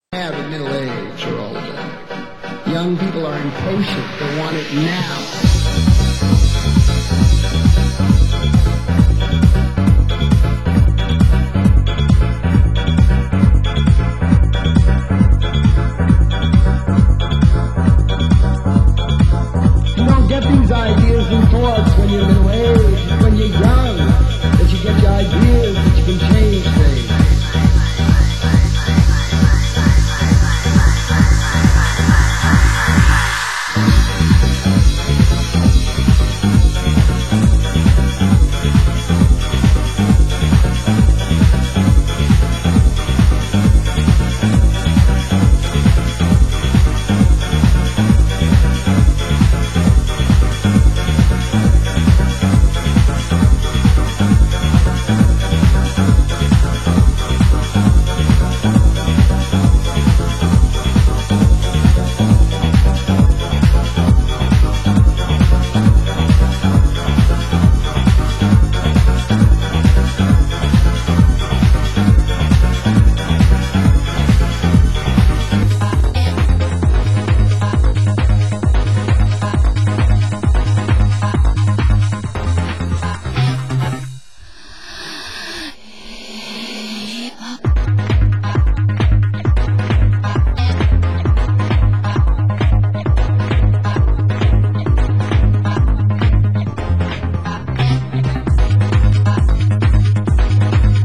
Genre: Tech House